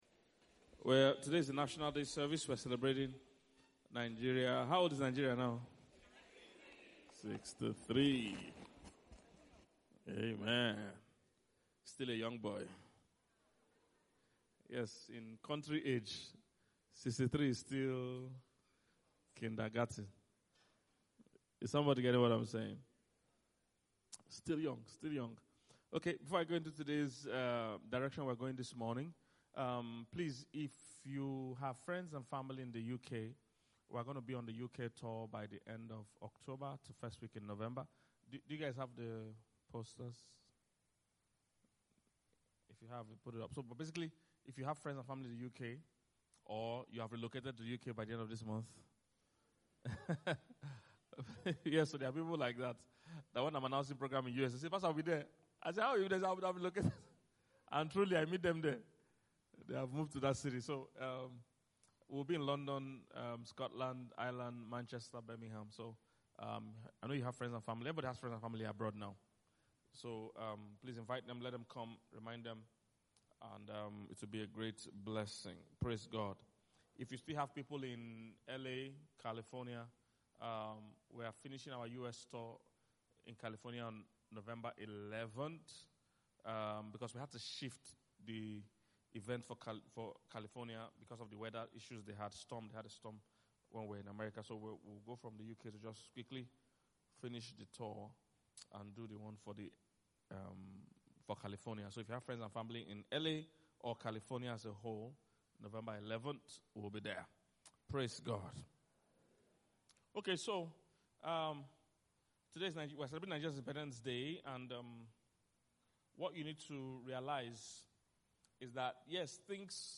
Independence Day Service